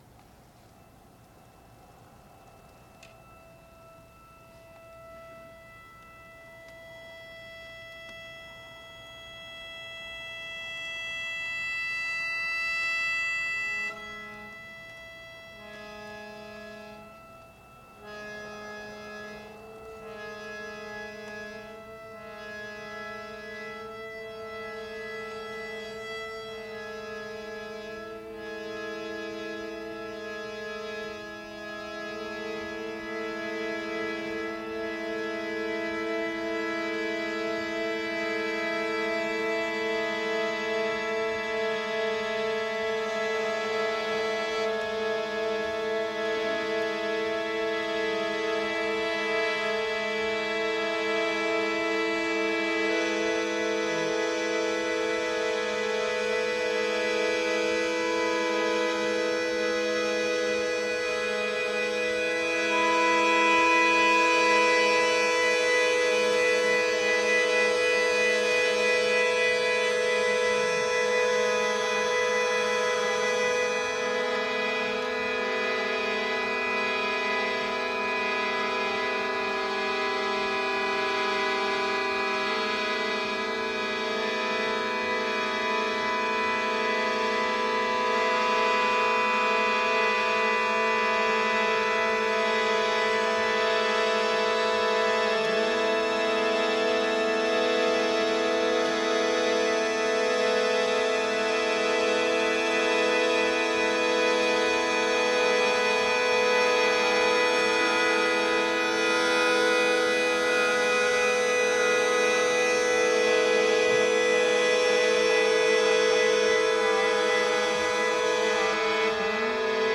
持続音を活かしたアコーディオンによる演奏と、バトゥカーダやクレズマーのリズムが交錯！